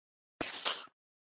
Звуки крипера
На этой странице собраны звуки крипера из Minecraft — от характерного шипения до взрыва.